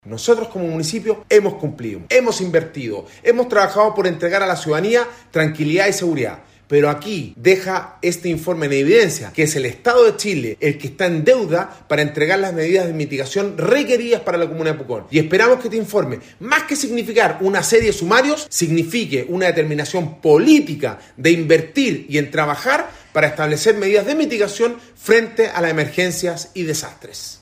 Alcalde-Sebastian-Alvarez-insiste-que-el-Municipio-cumplio-pero-no-el-Estado.mp3